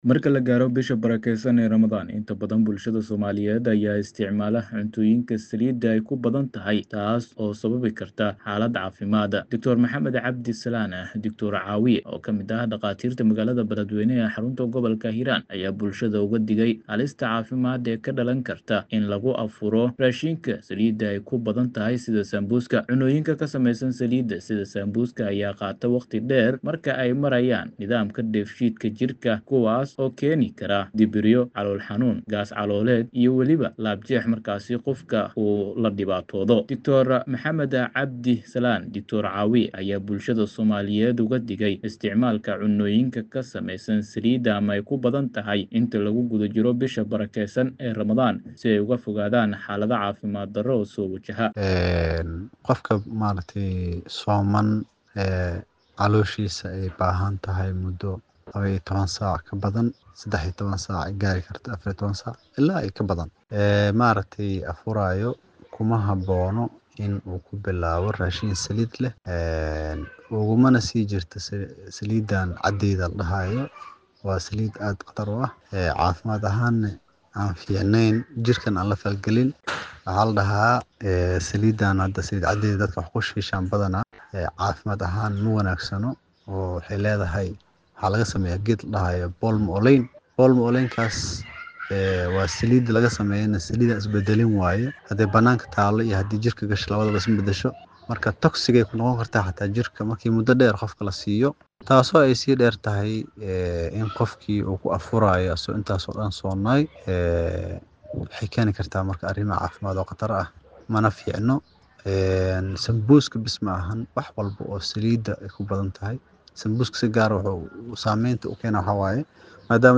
warbixintaan